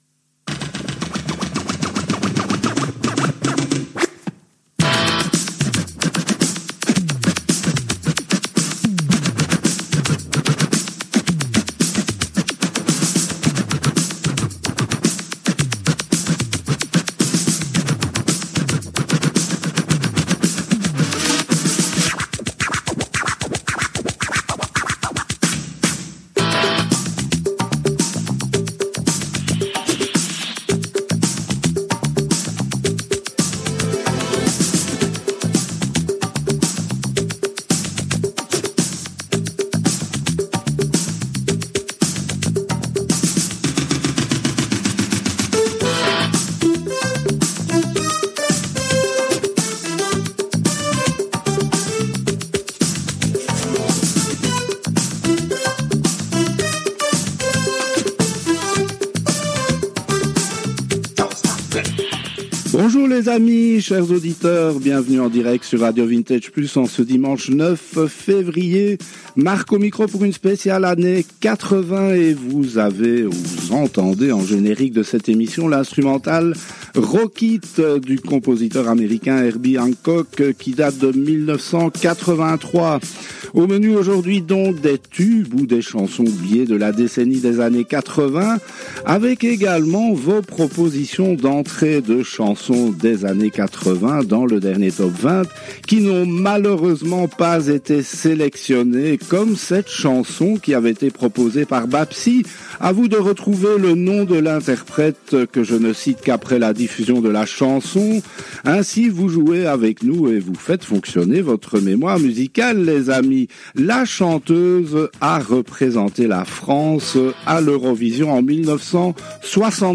C’est une émission spéciale à thème que RADIO VINTAGE PLUS a diffusée le dimanche 09 février 2025 à 10h en direct des studios de RADIO RV+ en BELGIQUE